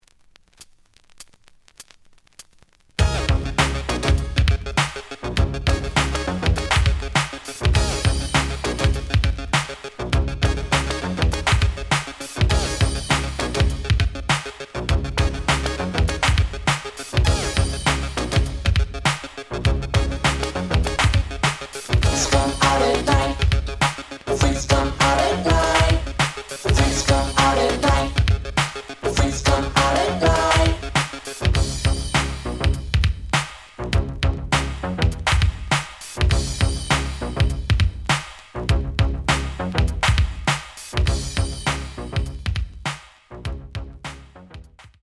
The audio sample is recorded from the actual item.
●Genre: Hip Hop / R&B
Slight edge warp.